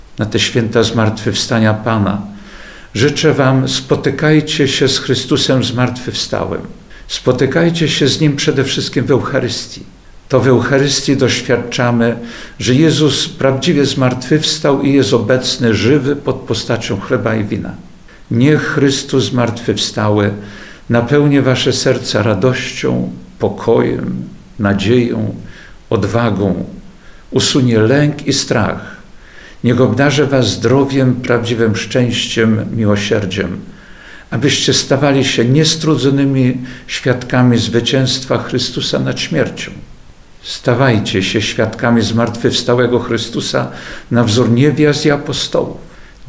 Życzenia wielkanocne księdza biskupa Jerzego Mazura: